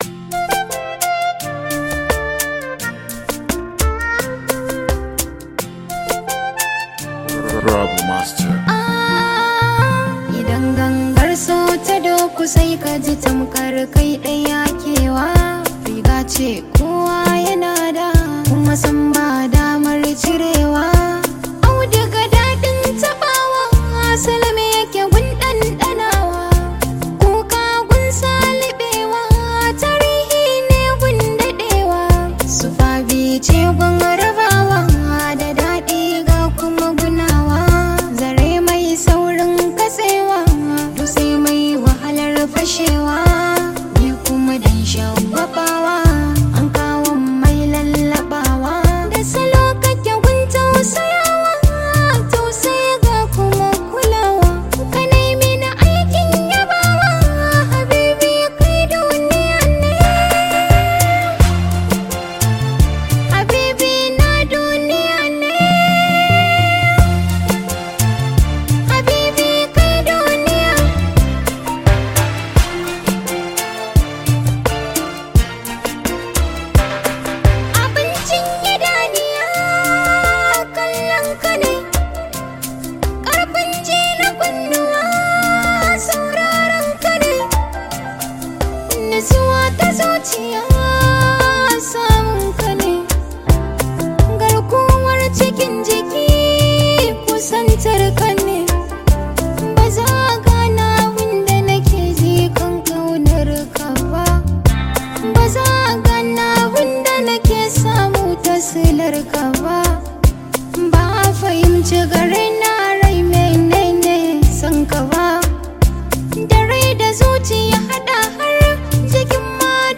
Category: Hausa Songs
a romantic song for first wives.